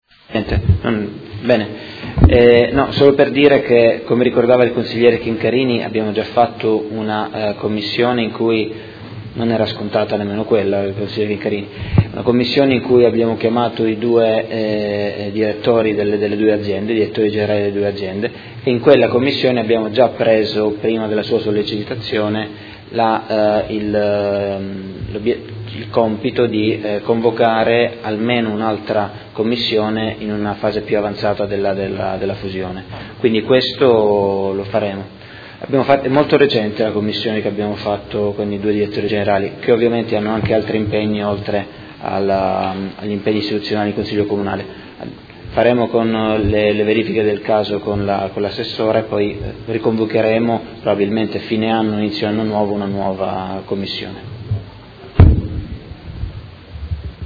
Seduta del 28/09/2017 Interrogazione del Gruppo Movimento cinque Stelle avente per oggetto: Riorganizzazione della rete Ospedaliera territoriale di Modena. Dibattito